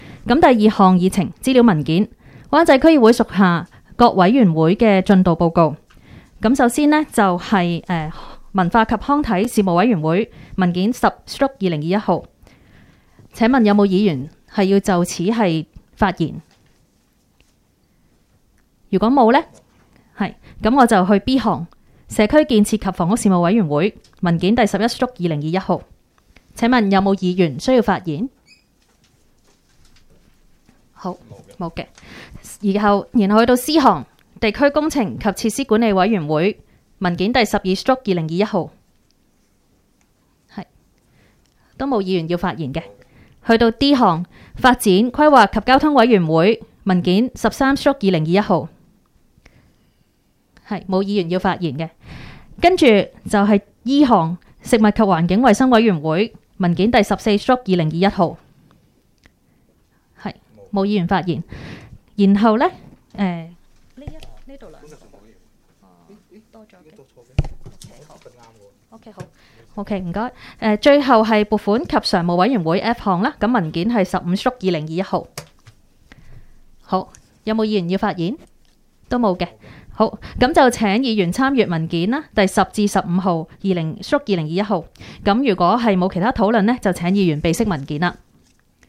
区议会大会的录音记录
湾仔区议会第十次会议
湾仔民政事务处区议会会议室